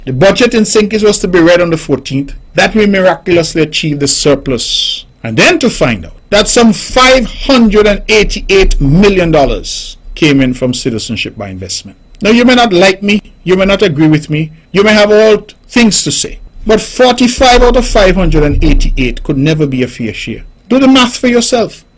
Later that evening, during the ‘On the Mark’ program, Premier of Nevis, Hon. Mark Brantley had this to say: